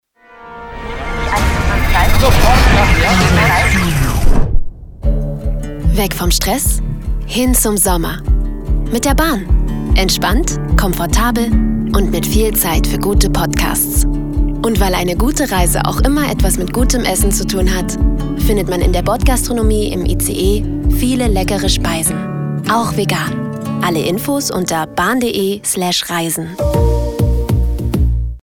sehr variabel
Jung (18-30)
Sächsisch
Commercial (Werbung)